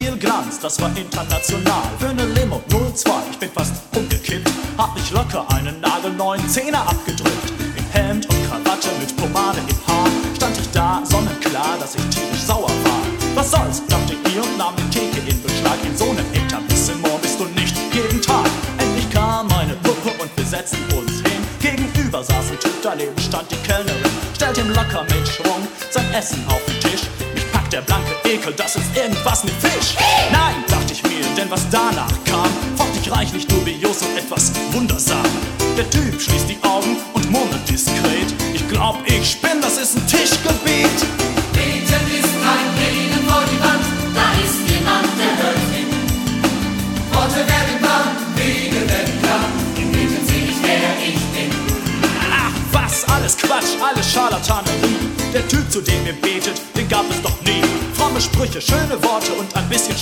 Gospel & Soul